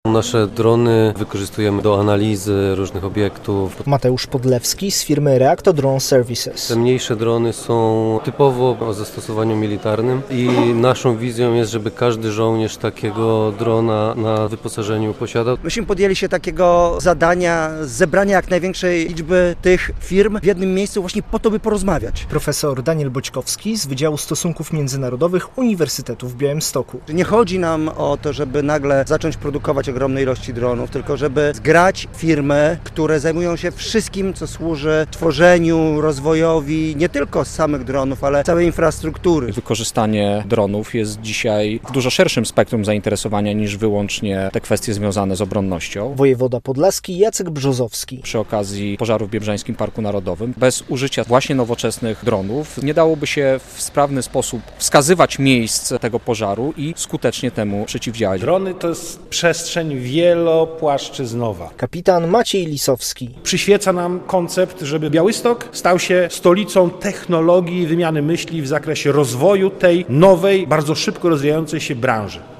Konferencja o dronizacji armi i służb